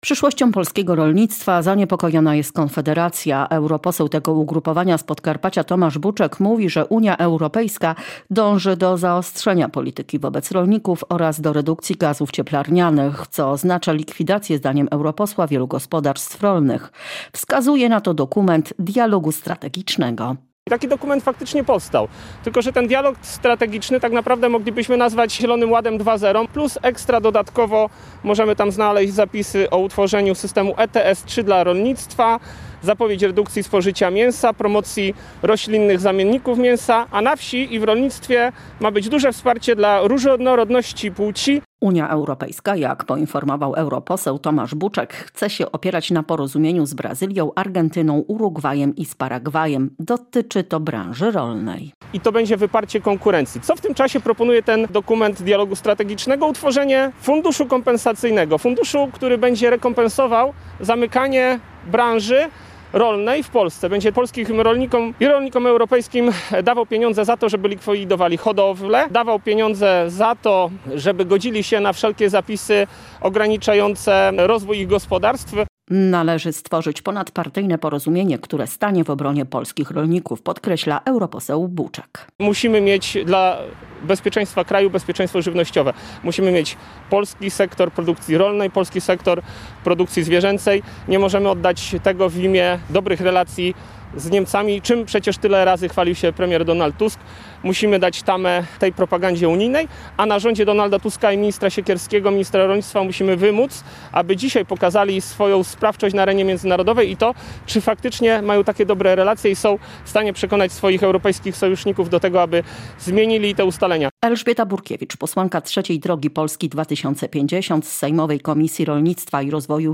konferencja.mp3